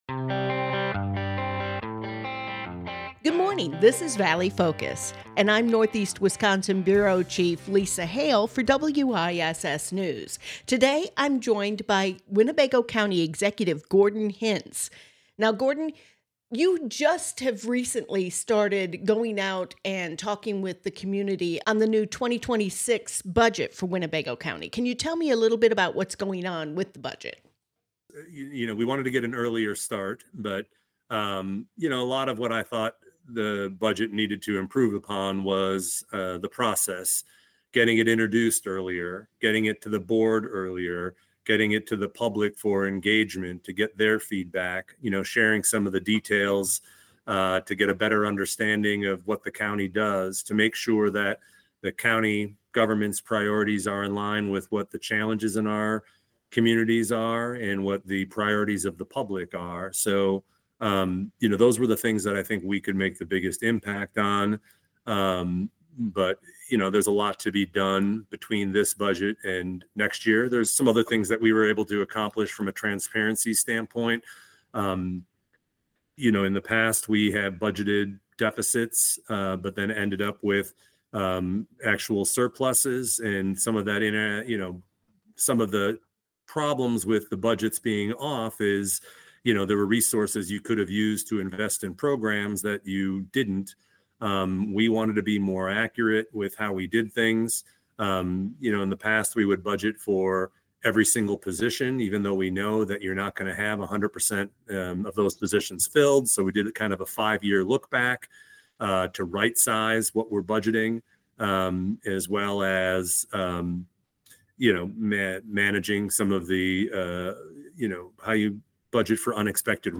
two part interview
wiss news